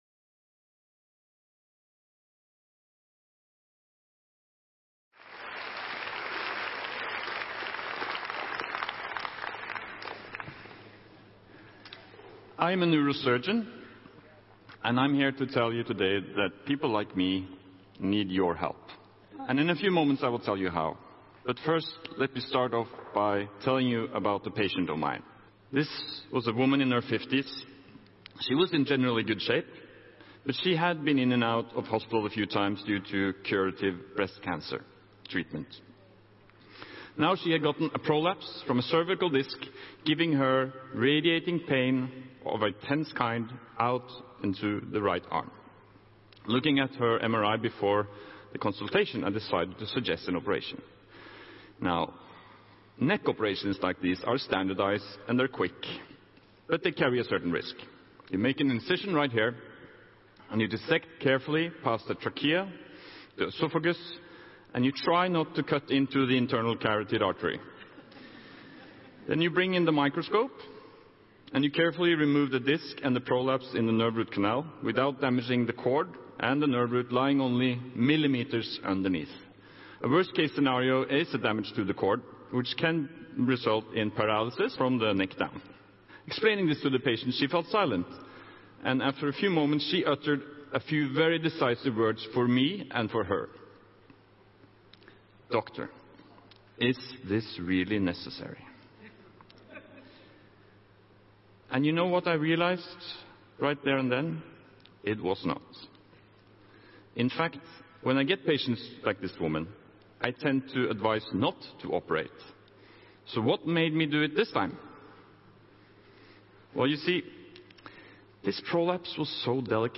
Why Your Doctor Needs Your Help to Battle Over-Treatment at TEDxOslo conference.